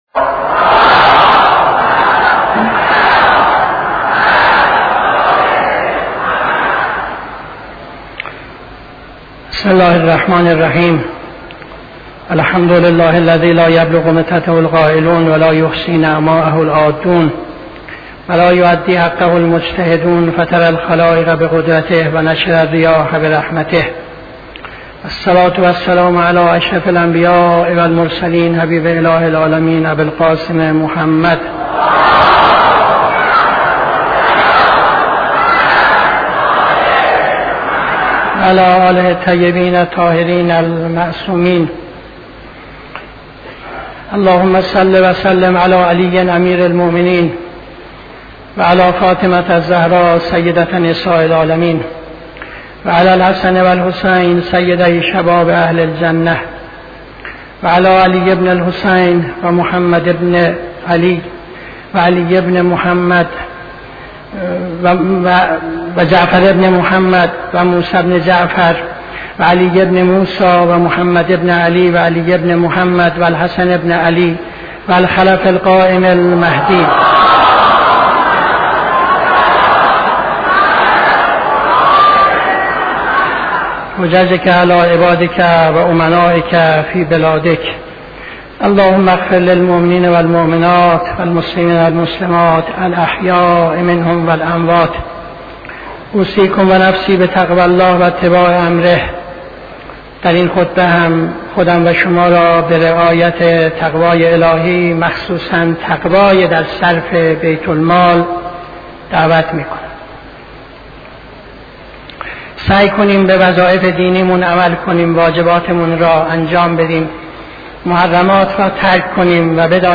خطبه دوم نماز جمعه 10-08-75